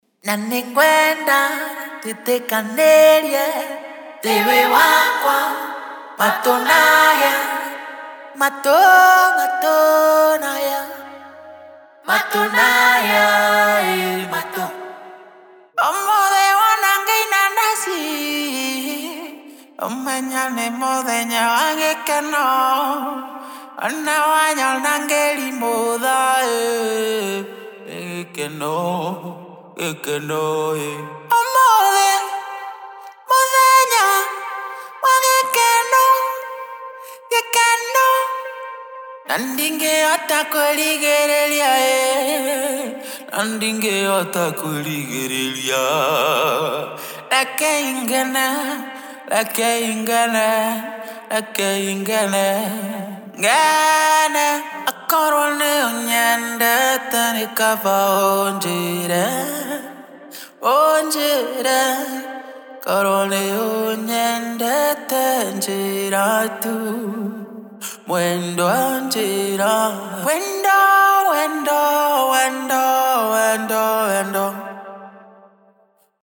Genre:Africa
このエクスクルーシブなコレクションは、ソウルフルでリズミカルな深みをプロダクションに加えるのに最適です。
伝統的なチャント、メロディックなフック、ユニークなアドリブボーカルなど、多彩なボーカルが揃っています。
ボーカルはドライとウェットのバージョンが含まれており、より柔軟に使用できる上、100%ロイヤリティフリーです。
48 Dry Male Vocal Loops
49 Wet Male Vocal Loops